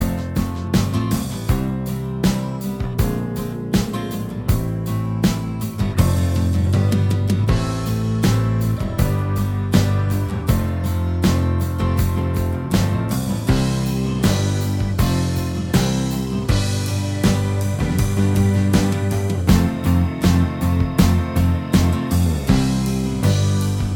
Minus Electric Guitar Pop (1990s) 2:45 Buy £1.50